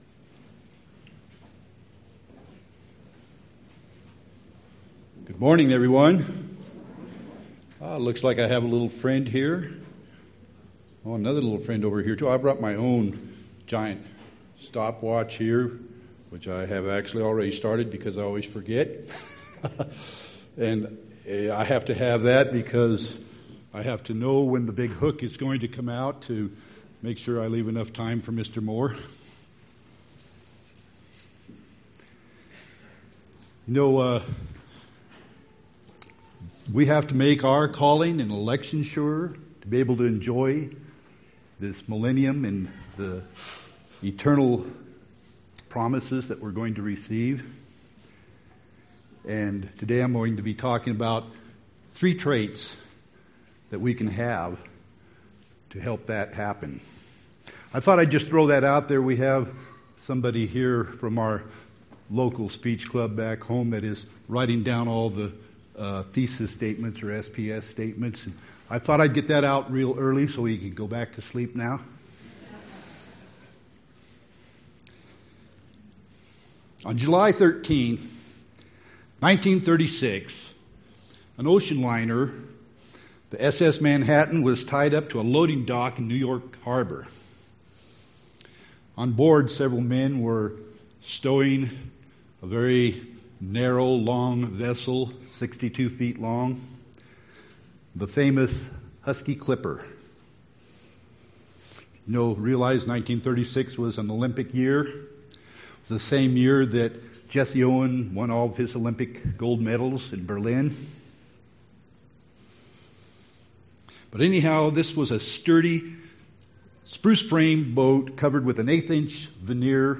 This sermon was given at the Steamboat Springs, Colorado 2019 Feast site.